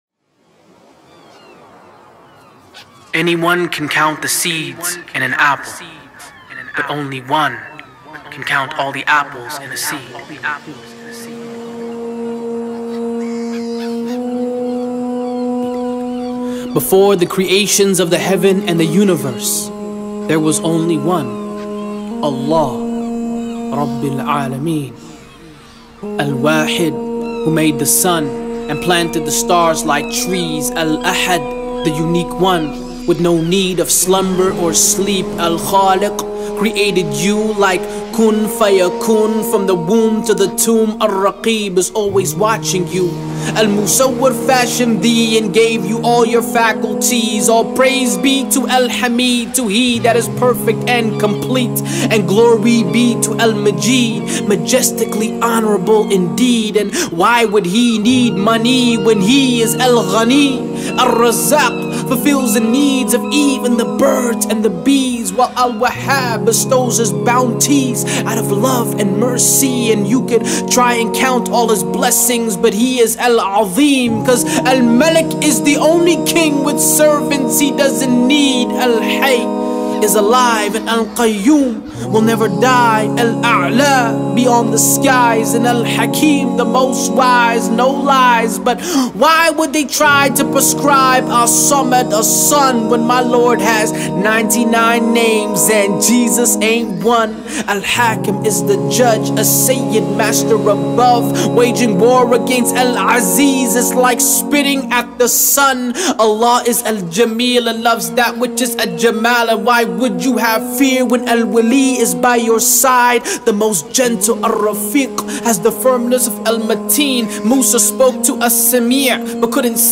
File:99 Beautiful Names - Spoken Word